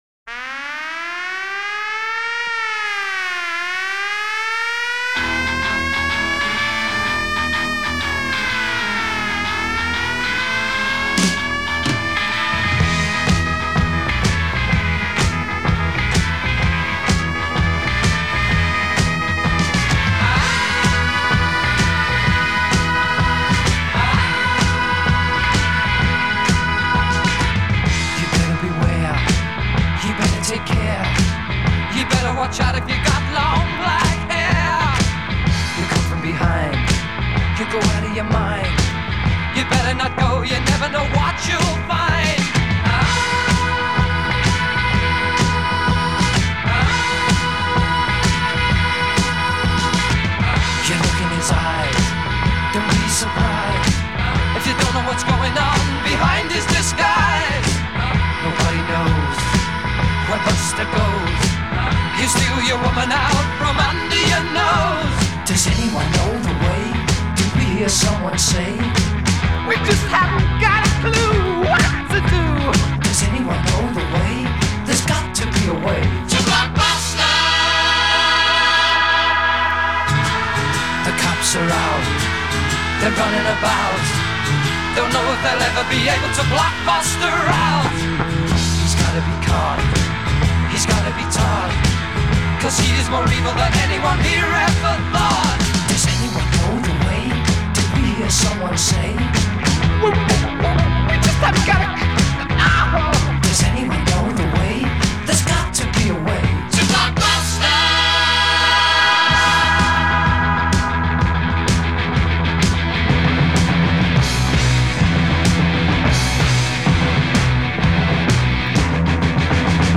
Genre: Glam Rock, Hard Rock